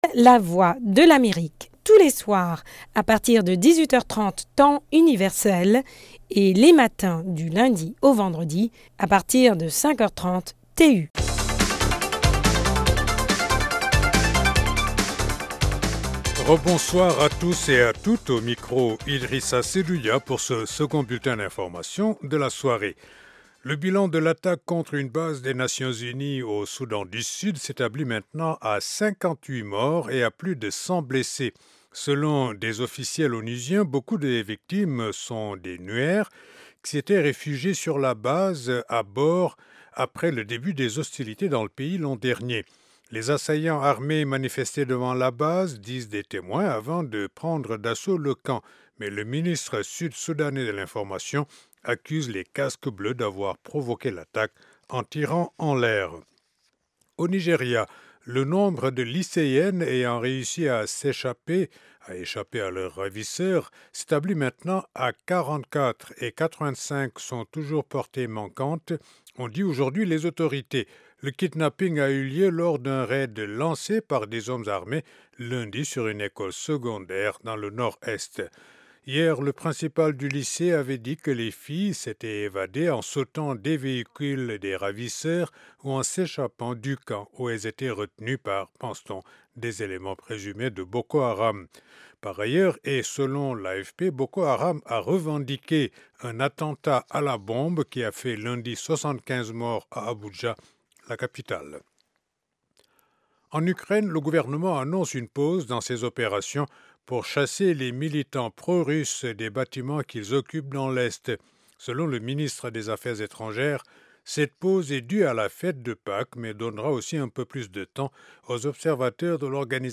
Dans cette Edition Spéciale RCA, Le MAGAZINE AU FEMININ a trois invitées centrafricaines, Gisèle Bedan, Porte-parole du gouvernement et Ministre de l’Education, de l’Enseignement Supérieur et de la Recherche Scientifique ; Marie-Noëlle Koyara, ministre d’Etat en Charge du Développement Rural ; et Marie-Reine Hassen, ex-ambassadeur de la Centrafrique au Sénégal.